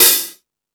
Space Drums(15).wav